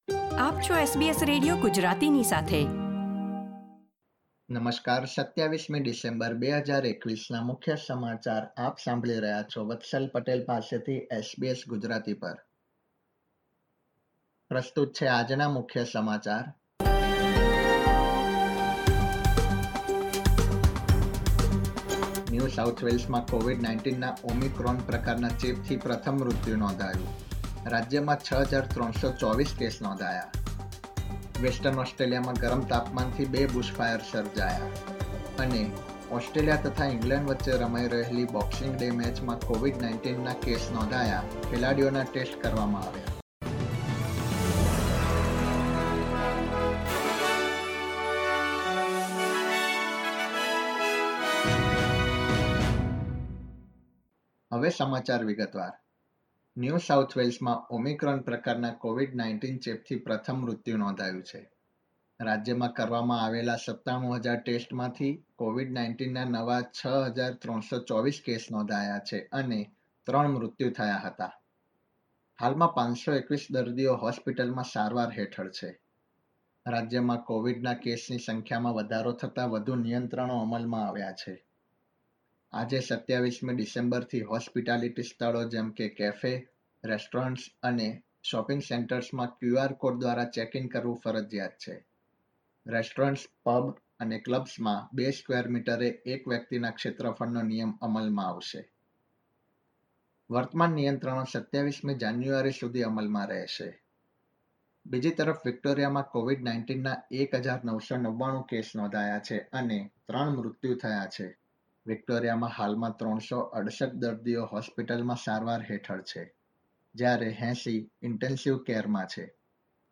SBS Gujarati News Bulletin 27 December 2021